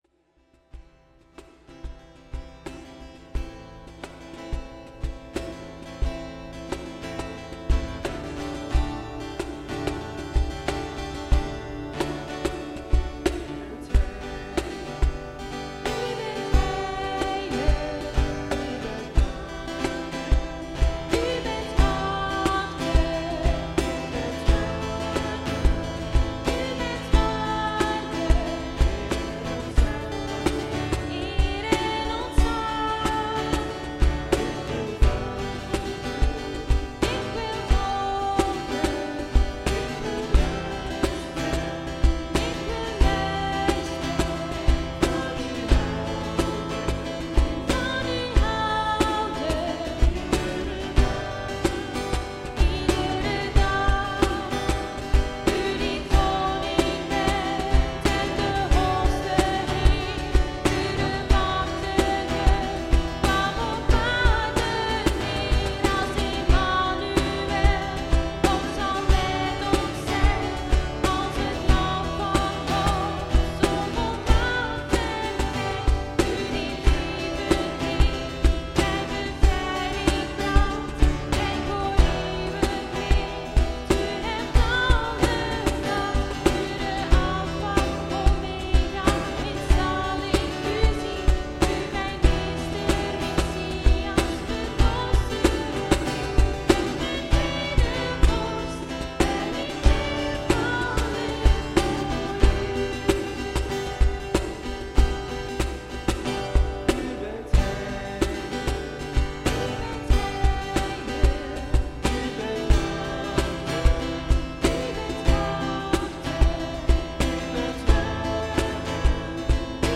>TIP >>Als u meteen de preek wil beluisteren schuif dan door tot 19:03